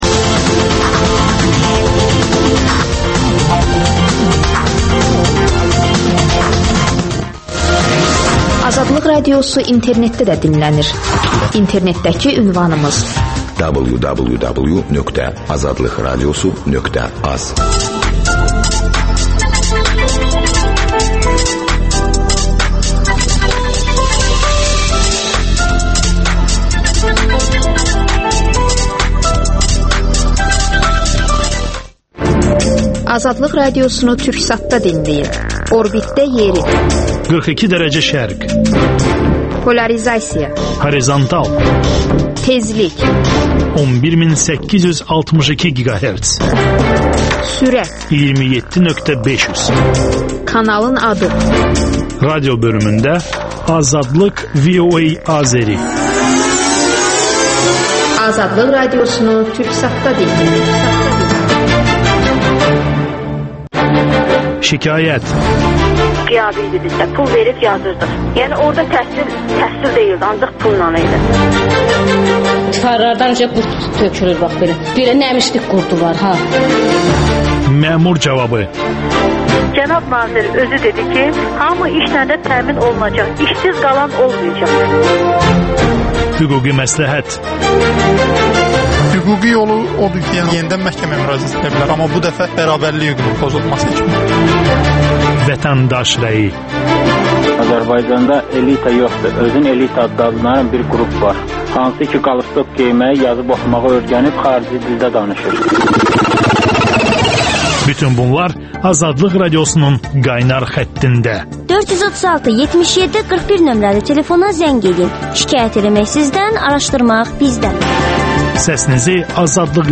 Debatda Azad Yazarlar Ocağından